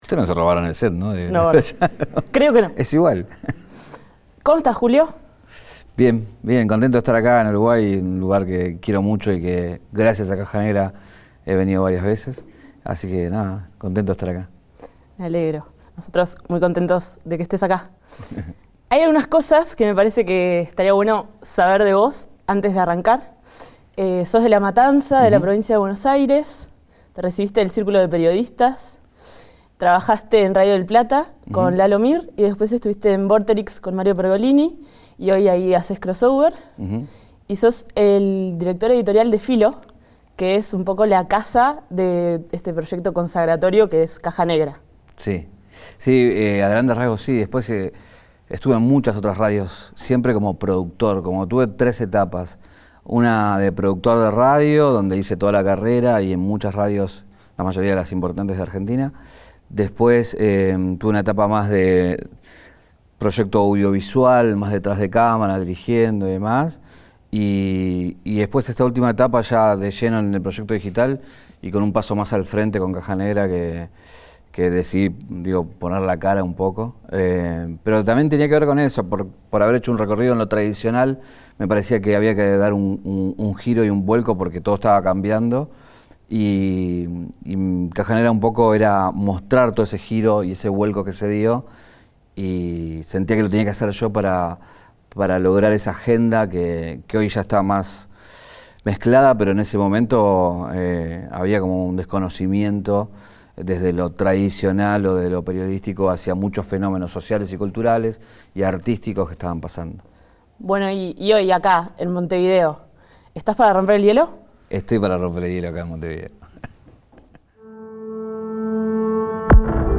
Universidad Católica del Uruguay / Entrevista